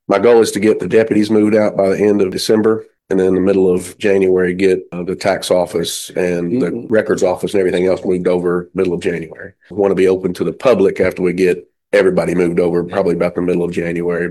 The new sheriff’s office may be opening next month.  Sheriff Matt Sanderson had a few words about it during this week’s fiscal court meeting.